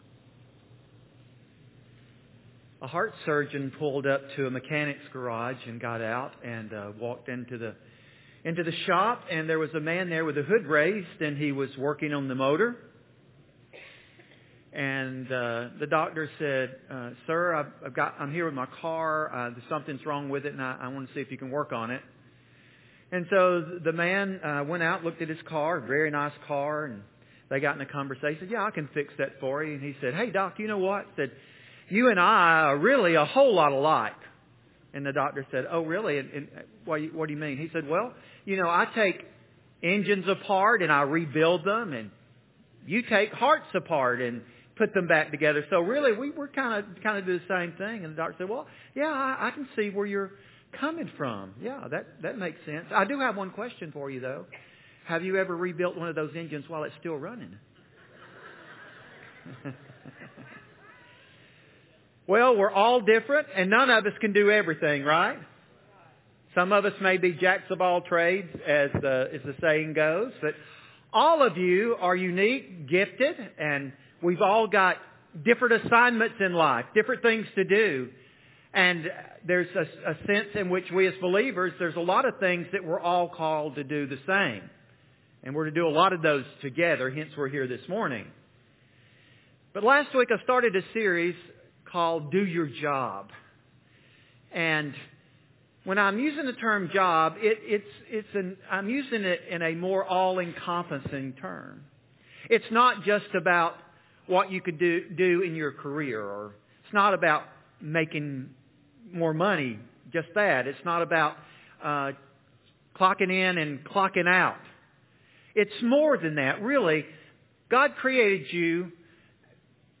Current Sermon Do Your Job! (Part 2) What the Bible says about the role of responsibility in our lives DO YOUR JOB!